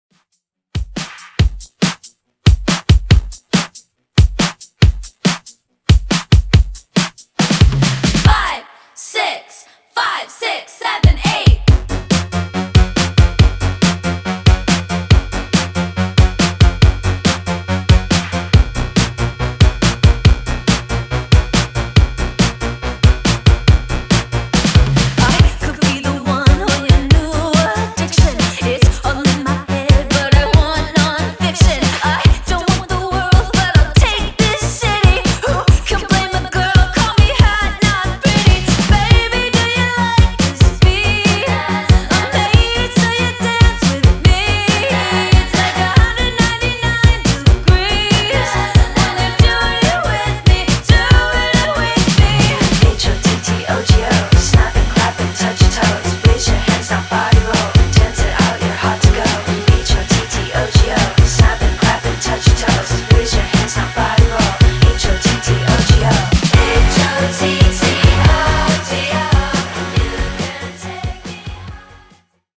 Genres: BASS HOUSE , RE-DRUM
Clean BPM: 128 Time